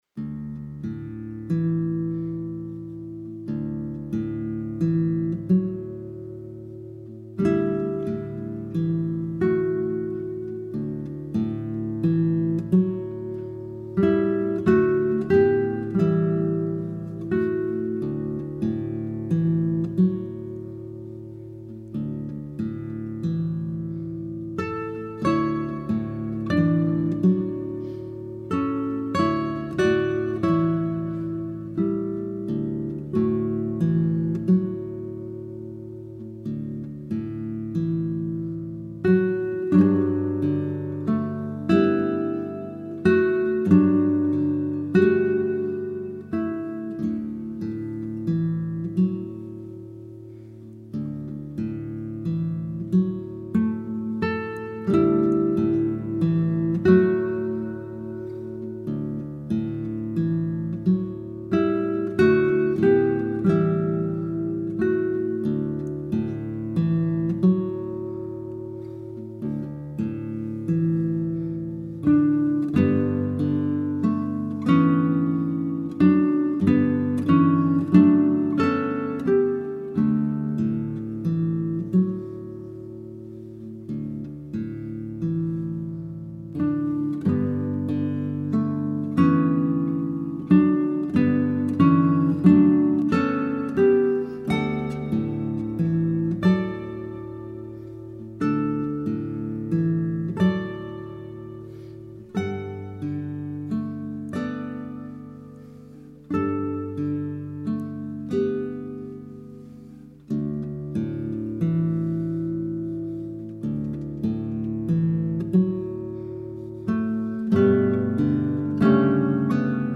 Youth Orchestra
Performed online by the Westmorland Youth Orchestra.